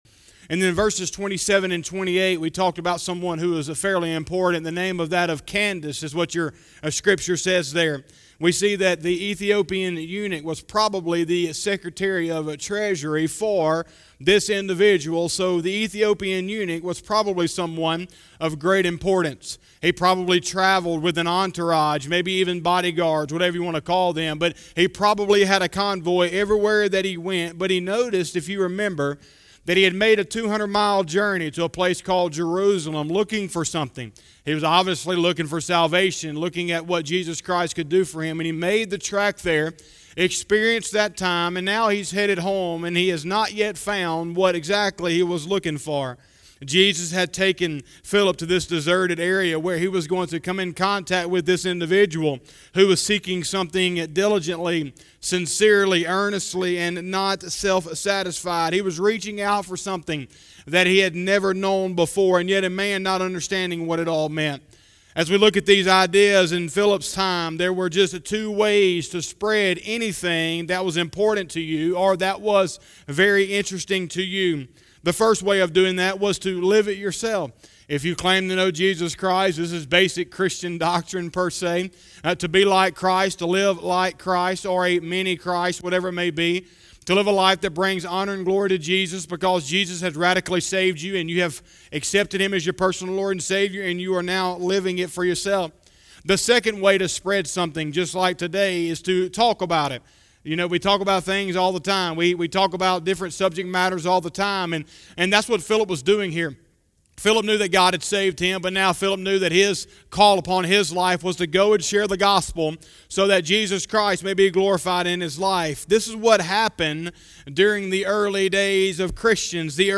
07/19/2020 – Sunday Morning Service